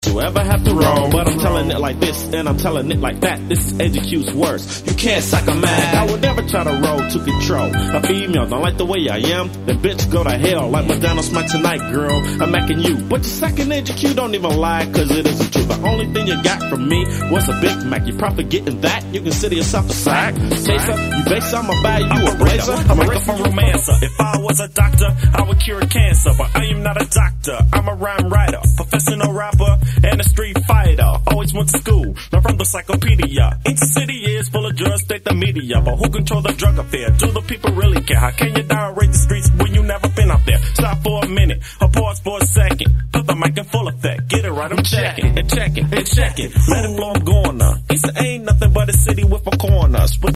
gangsta rap